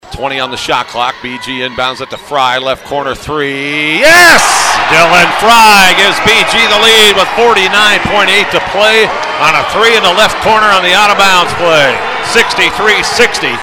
Radio Call